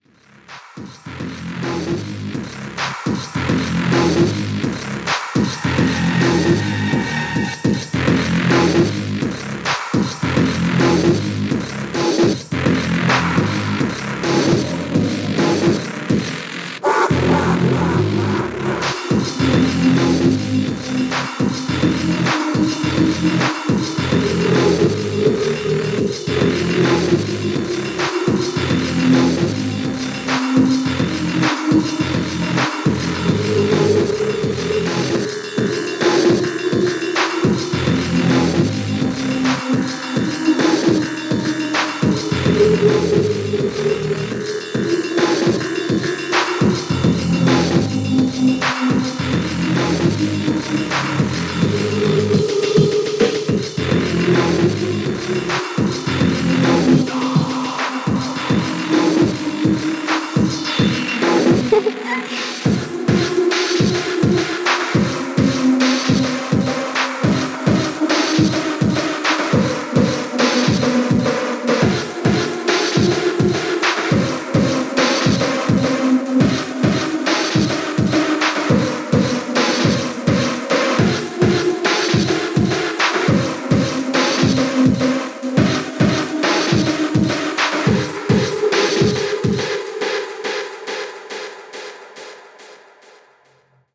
ノイジーなサウンドがハマります（笑）